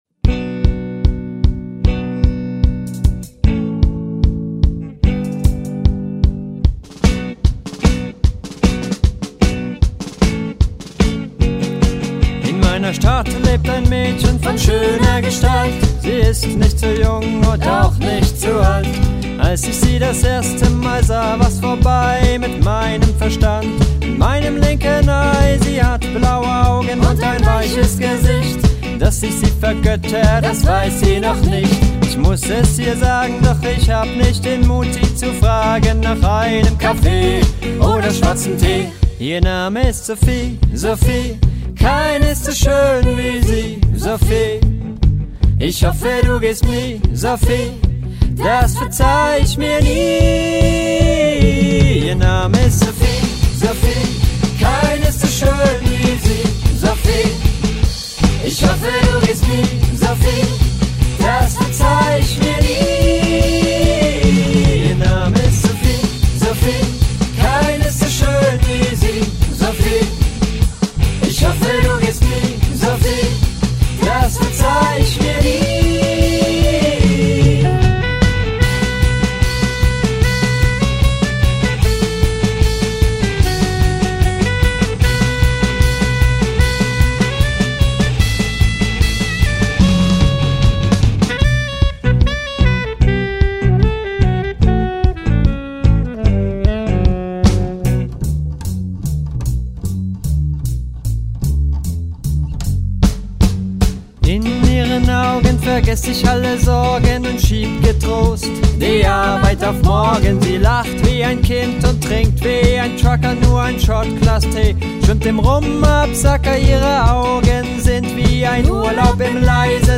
Saxophone
E-Guitar